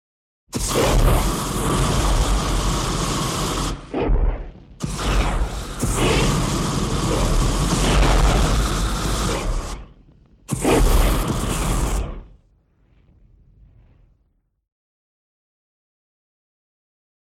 На этой странице собраны реалистичные звуки огнемета — от гула воспламенения до рева пламени.
Грозный звук запуска мощного огнемета